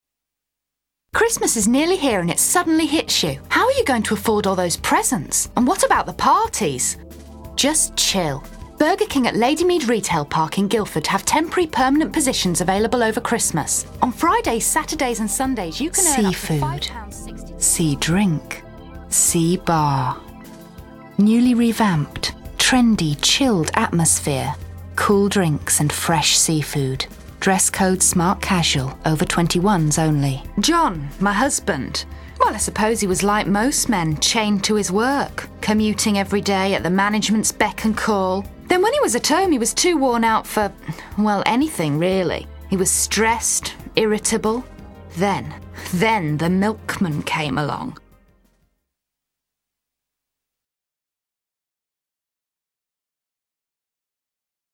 I have a bright, natural tone, and am an excellent sight-reader.
Radio commercials (Selection)